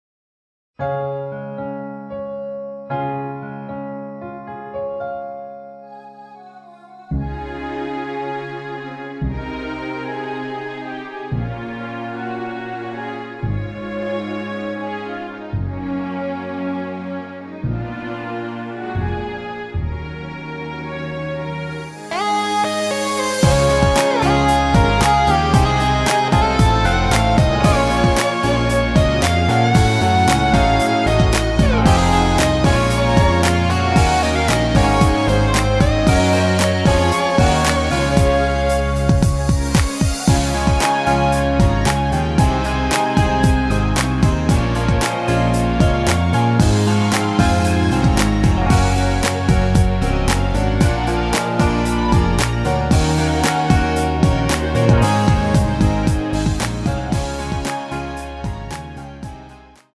전주 없이 시작 하는 곡이라 노래 하시기 편하게 전주 2마디 만들었습니다.
원곡의 보컬 목소리를 MR에 약하게 넣어서 제작한 MR이며